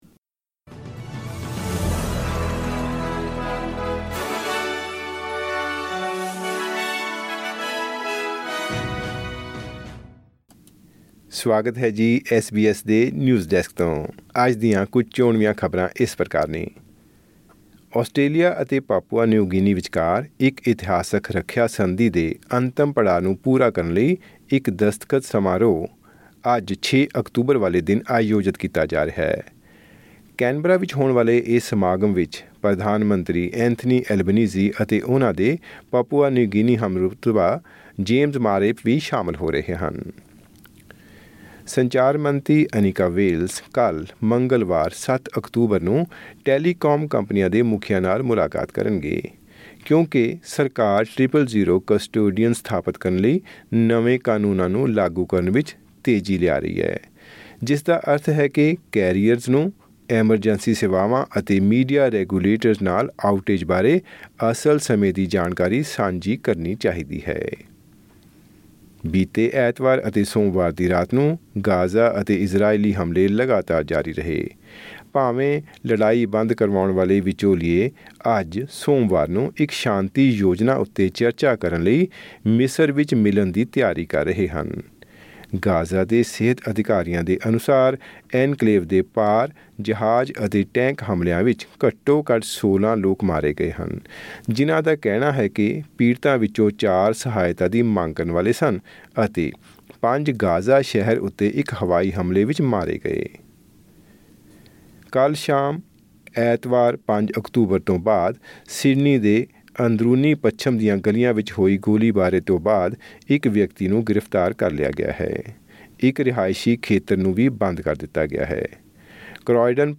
ਖ਼ਬਰਨਾਮਾ: ਆਸਟ੍ਰੇਲੀਆ ਤੇ ਪਾਪੂਆ ਨਿਊ ਗਿਨੀ ਵਿਚਕਾਰ ਨਵਾਂ ਇਤਿਹਾਸਕ ਰੱਖਿਆ ਸਮਝੋਤਾ